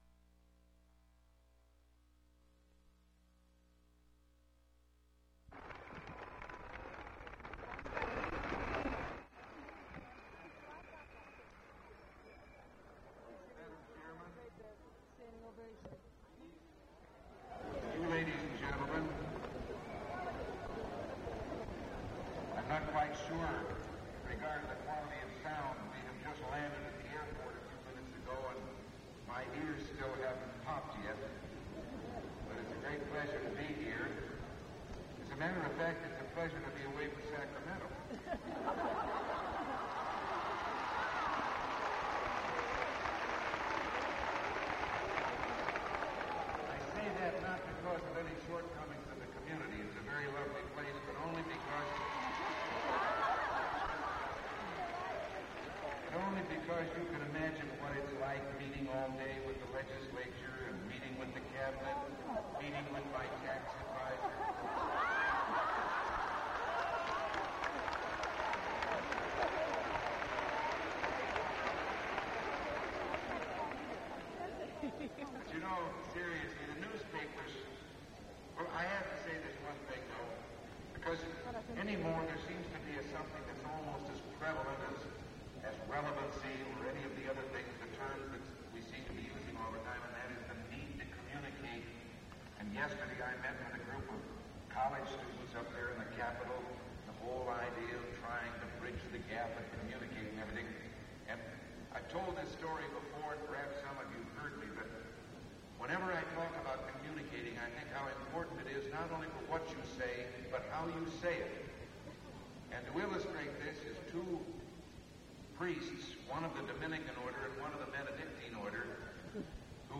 Ronald Reagan’s speech for PTA at Disneyland, Anaheim, California, Dr. Wilson Riles speech
MP3 Audio file (Ronald Reagan's Speech only)